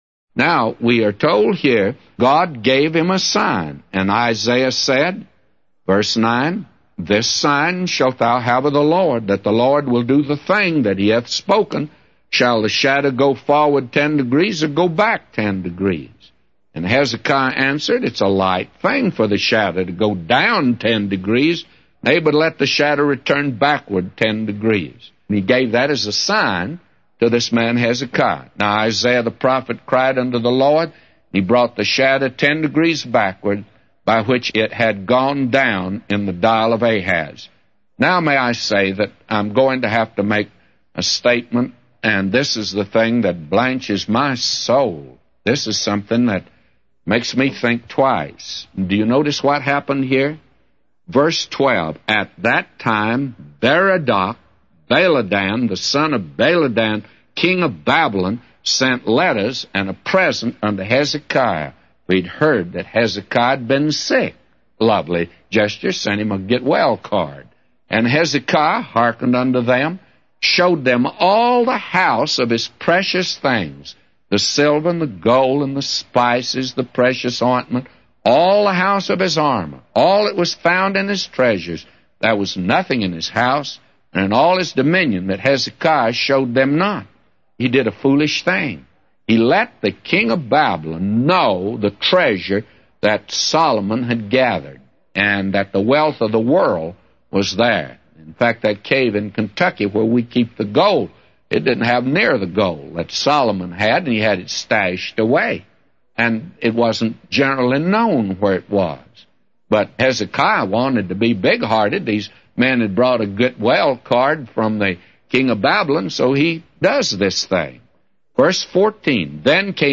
A Commentary By J Vernon MCgee For 2 Kings 20:9-999